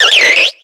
Cries
CHERUBI.ogg